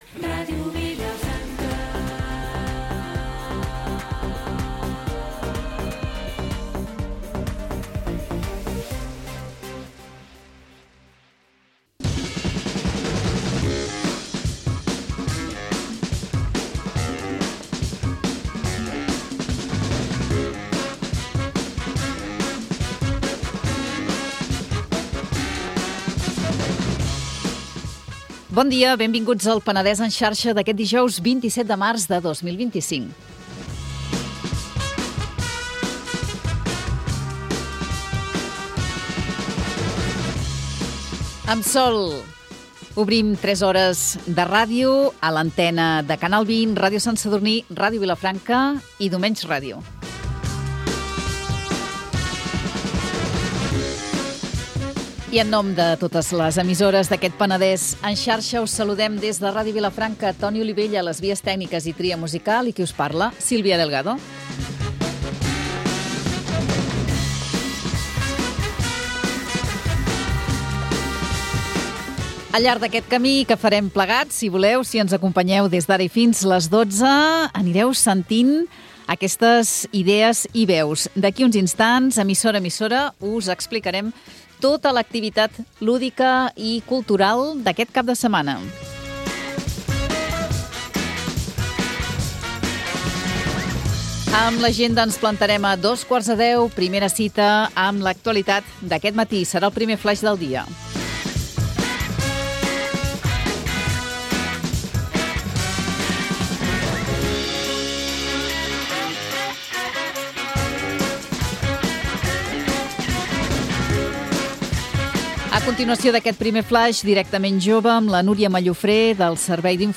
Magazín matinal